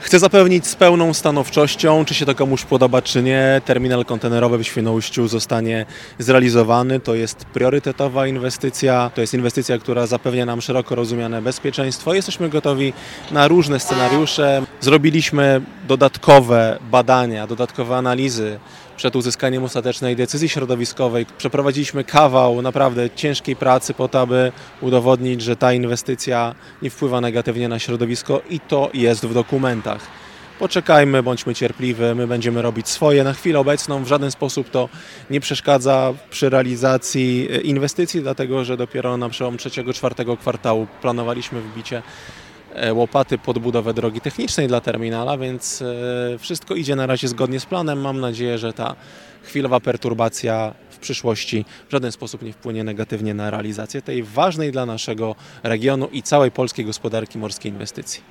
Wiceminister infrastruktury Arkadiusz Marchewka w wypowiedzi dla Twojego Radia uspokaja i zapewnia, że inwestycja nie jest zagrożona: